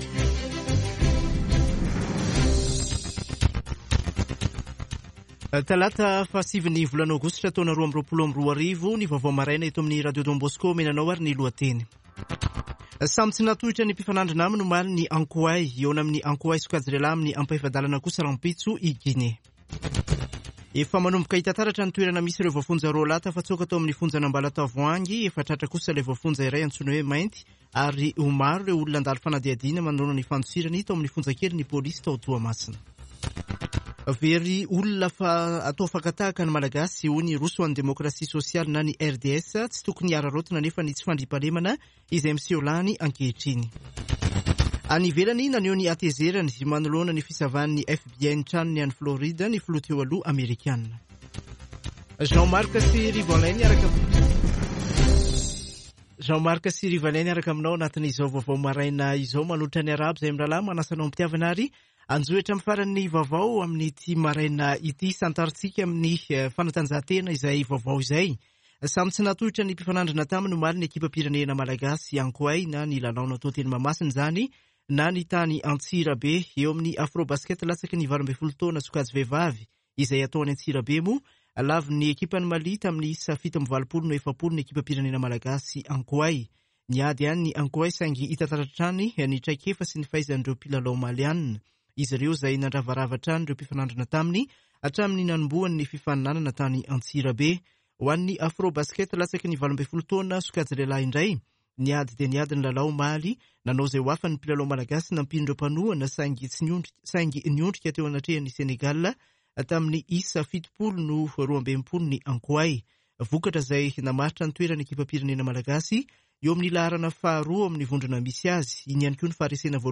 [Vaovao maraina] Talata 09 aogositra 2022